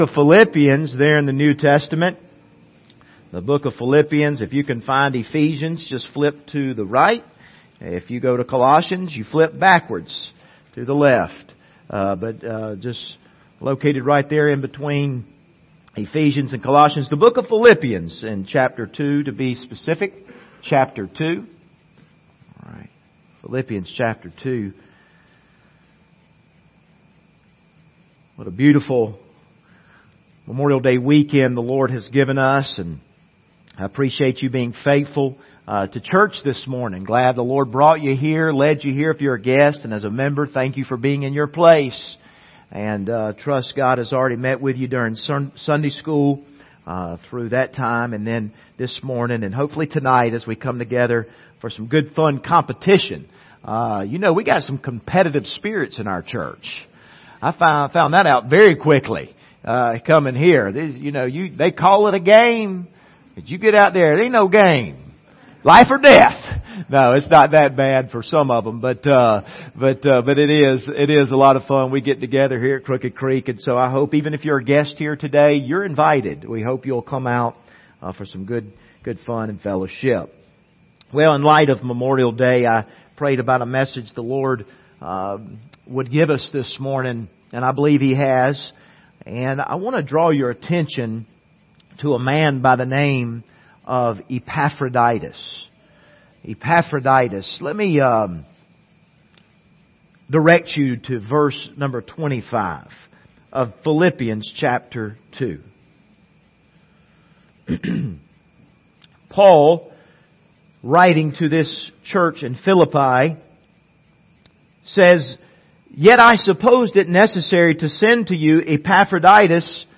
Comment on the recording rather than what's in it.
Passage: Philippians 2:25-30 Service Type: Sunday Morning Topics